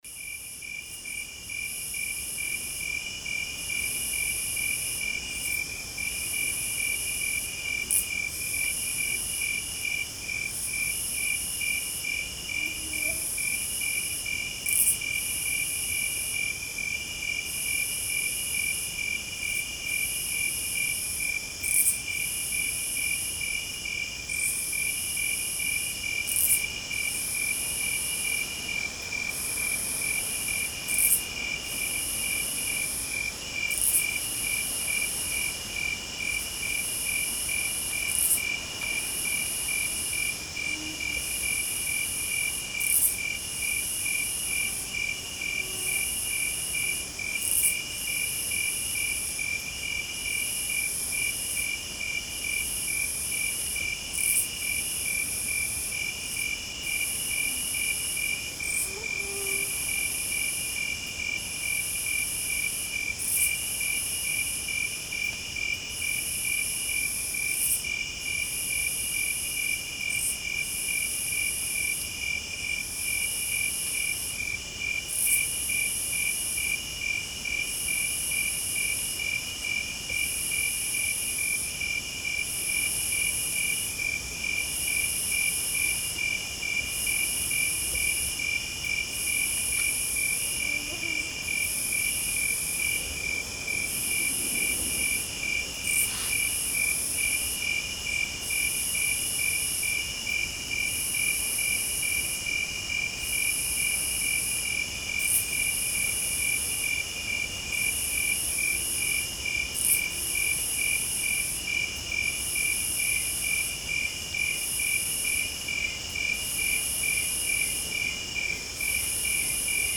Звуки леса
Звуки лісу ввечері: